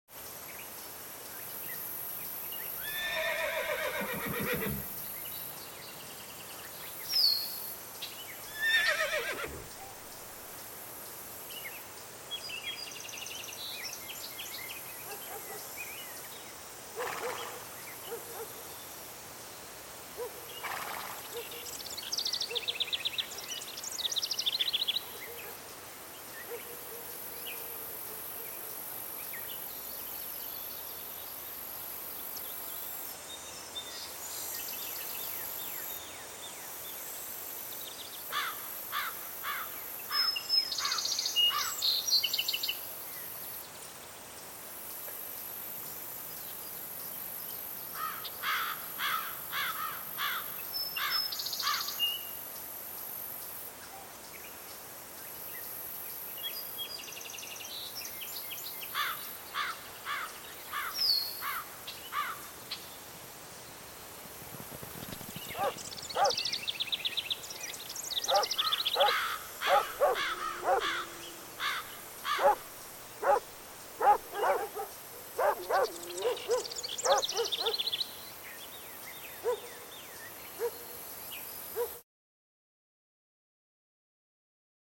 دانلود آهنگ پرنده 11 از افکت صوتی انسان و موجودات زنده
جلوه های صوتی
دانلود صدای پرنده 11 از ساعد نیوز با لینک مستقیم و کیفیت بالا